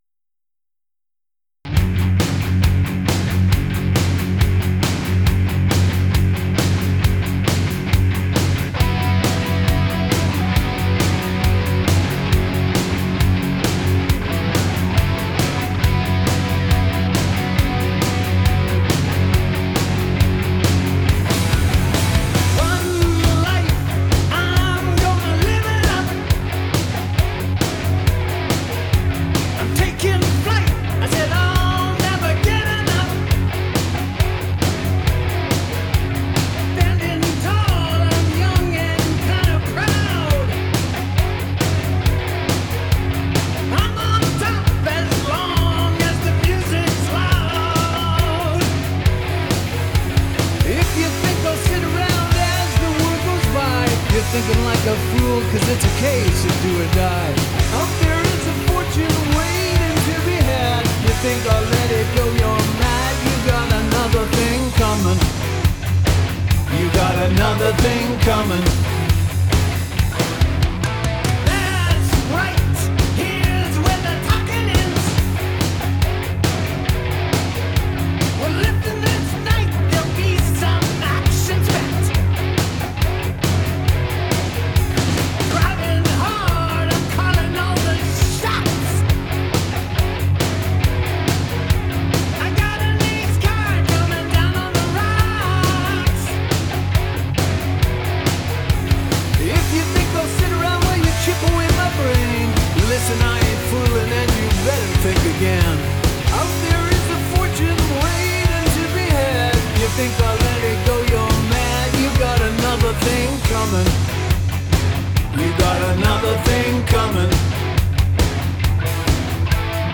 Added bass, vocals and remix